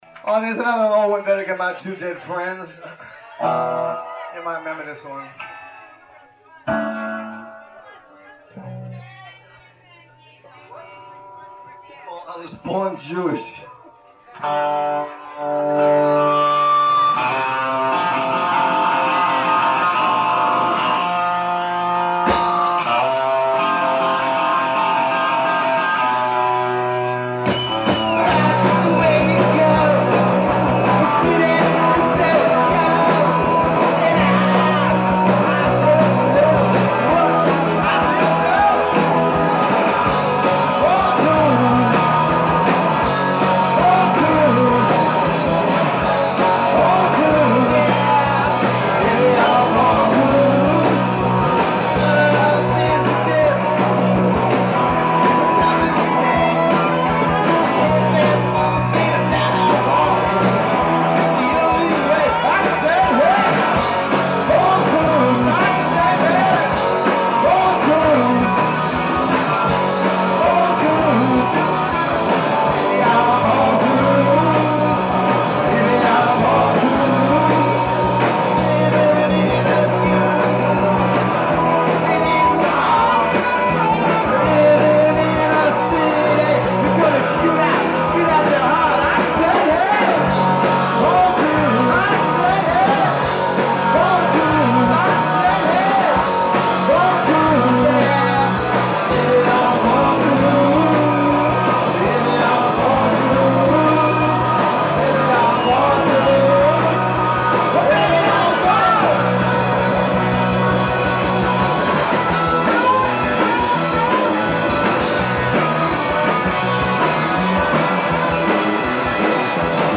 guitar/vocals
bass/vocals
drums
J.C. Dobbs, Philadelphia 1-25-92